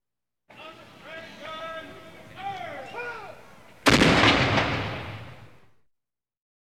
На этой странице собраны реалистичные звуки выстрелов из пушек разной мощности.
Гром выстрела корабельного орудия